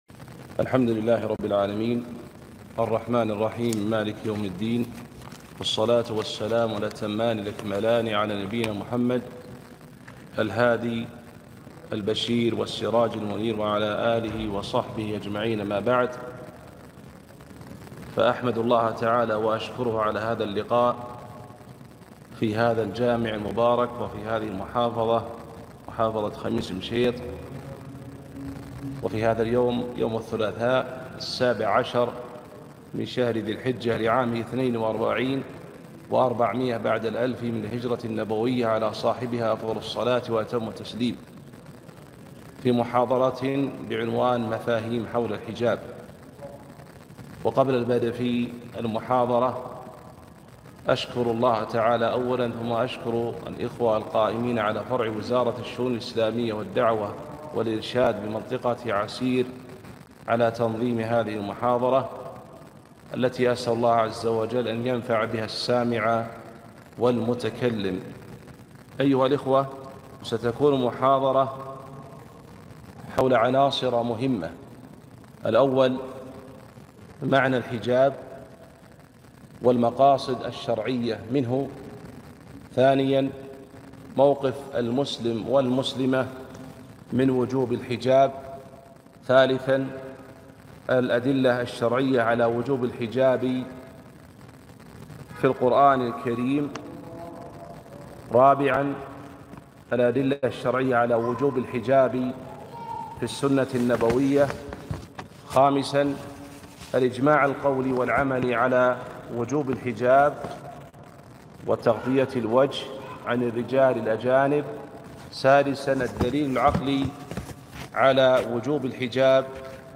كلمة - مفاهيم حول الحجاب